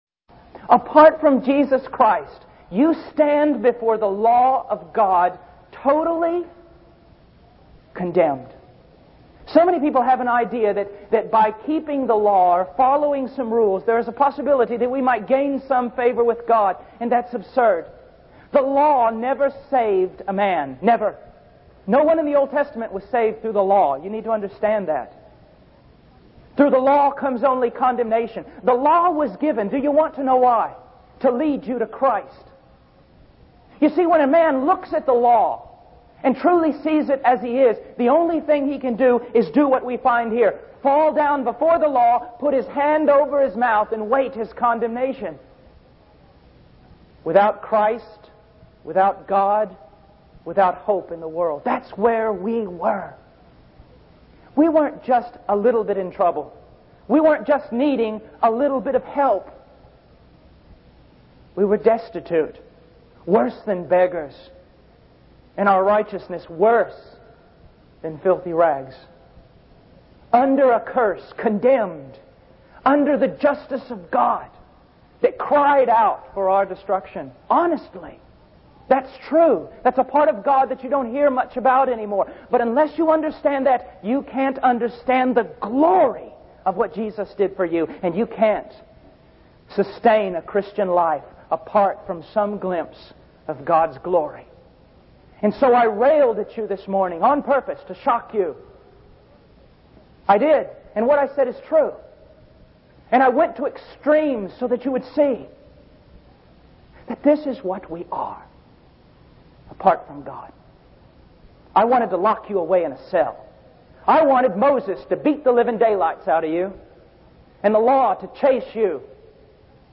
In this sermon, the speaker emphasizes the importance of recognizing our desperate need for salvation and the lack of tears in modern-day conversions. He highlights the need to weep over our sinfulness and see ourselves as abominations before the holiness of God.